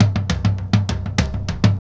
28DR.BREAK.wav